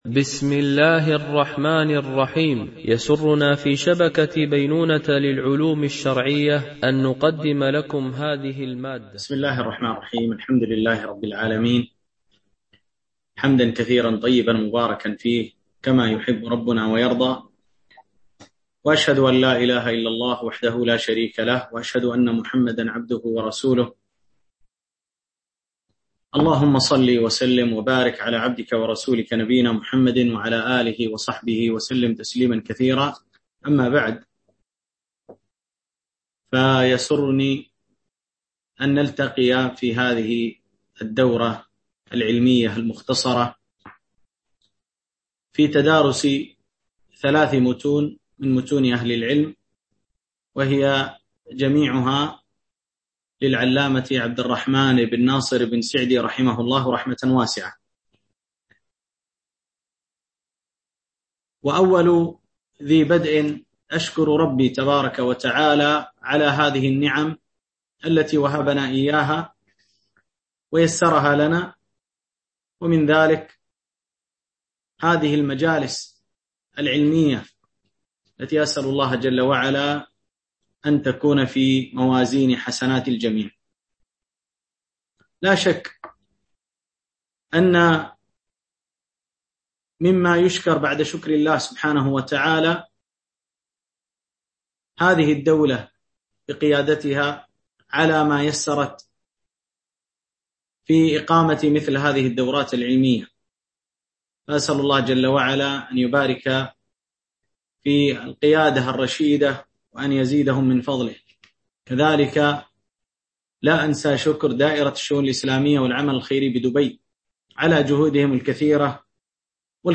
دورة علمية عن بعد